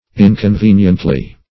Inconveniently \In`con*ven"ient*ly\, adv.